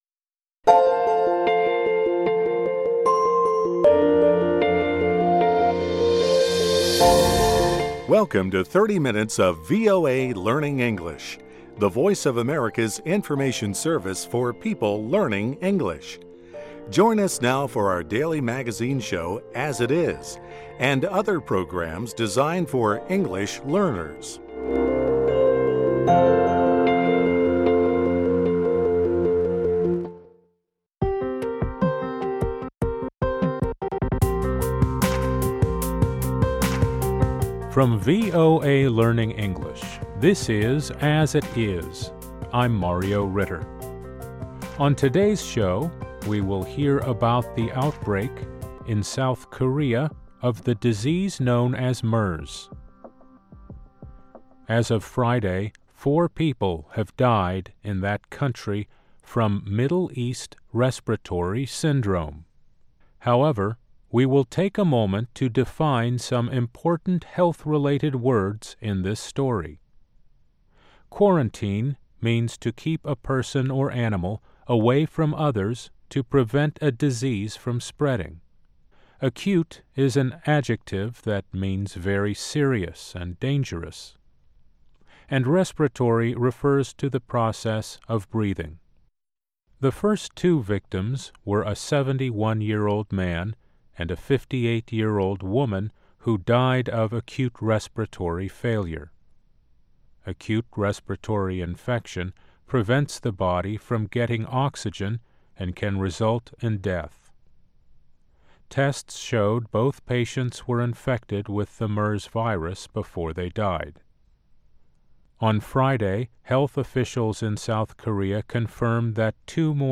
Learning English use a limited vocabulary and are read at a slower pace than VOA's other English broadcasts. Previously known as Special English.